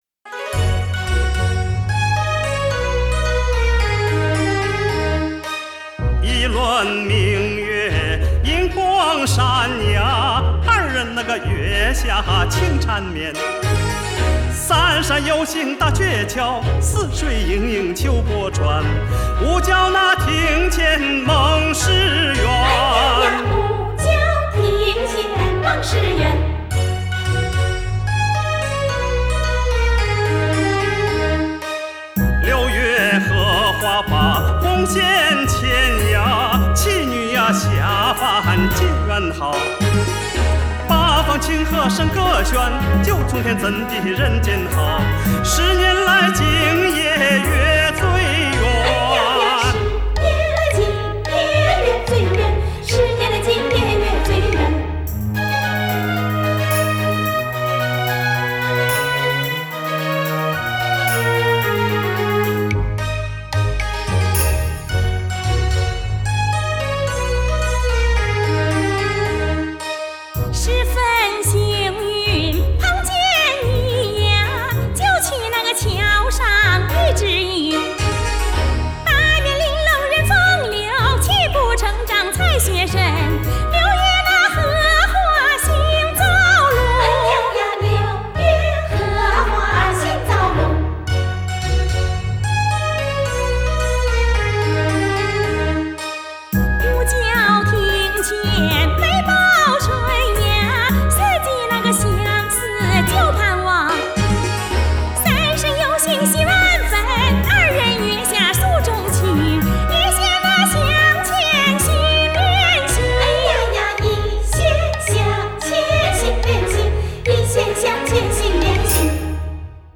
晋中秧歌剧